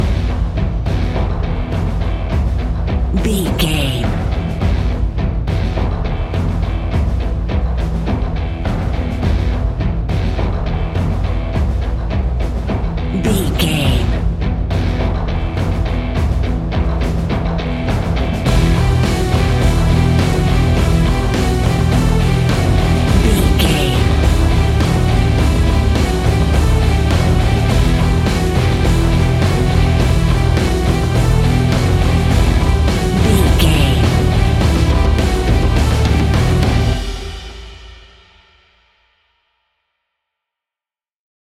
Epic / Action
Fast paced
Aeolian/Minor
hard rock
dirty rock
instrumentals
Heavy Metal Guitars
Metal Drums
Heavy Bass Guitars